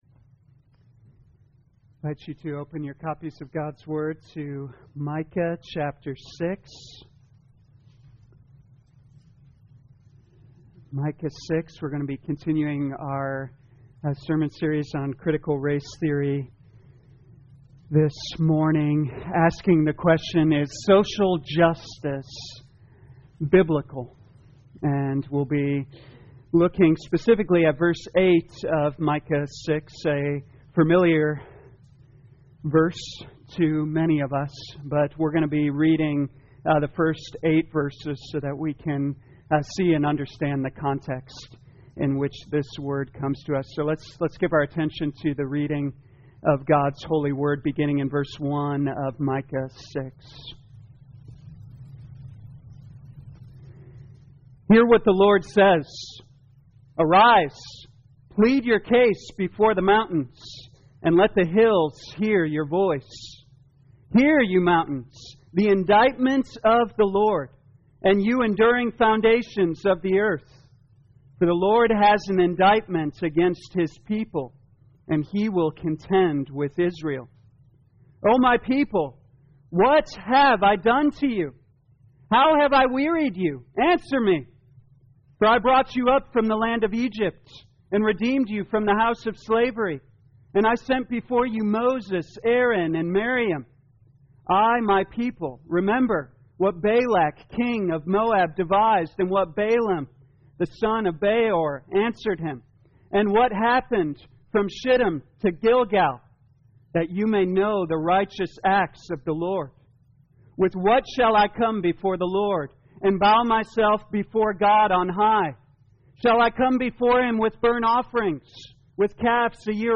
2021 Micah Critical Race Theory Morning Service Download